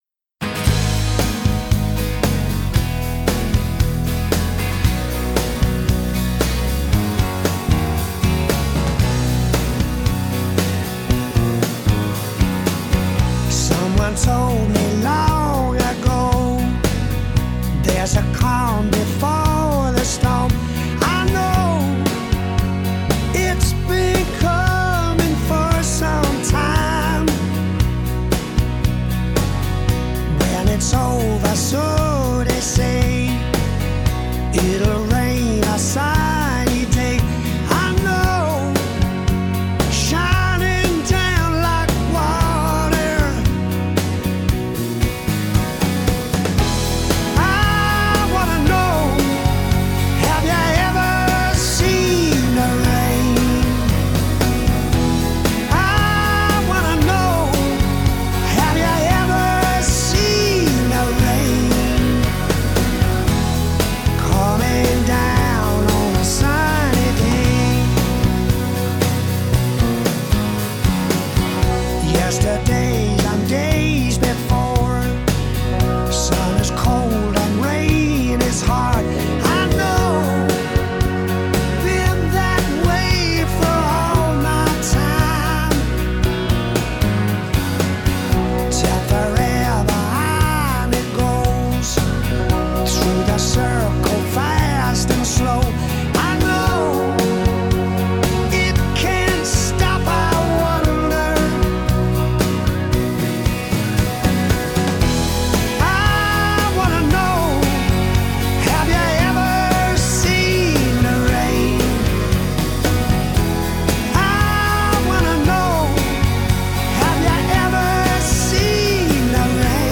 un poco de rock y una melodía homenaje